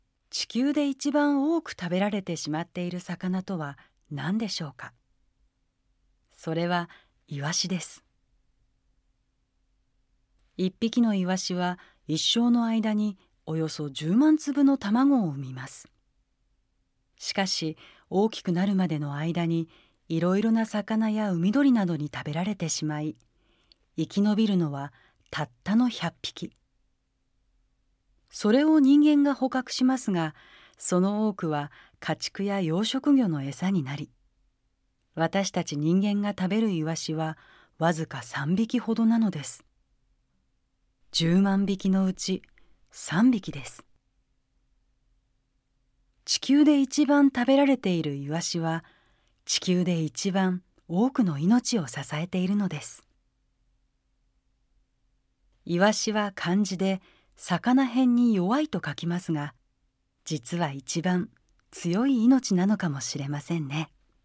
音声ガイドナレーター：宇賀なつみ